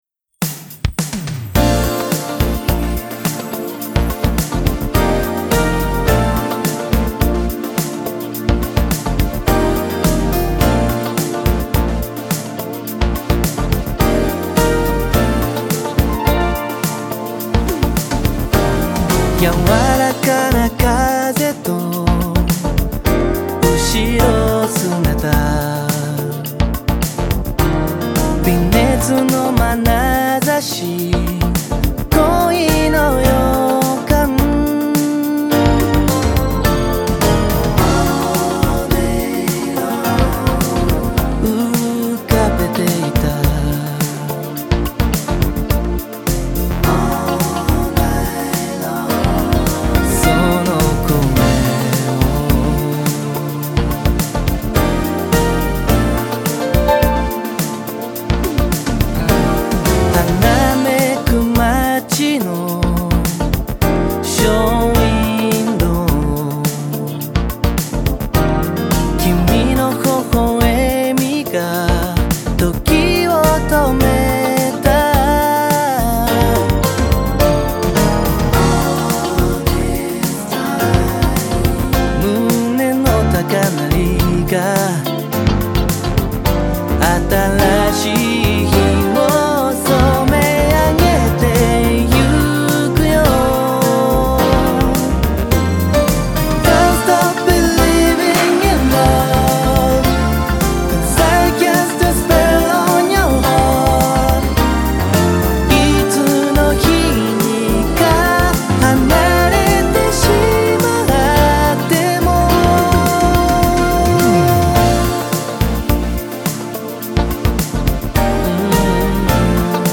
新世代AORユニット
J-POP / CITY POP / AOR / Light Mellow
Bass
Drums
Guitars
Tenor Saxophone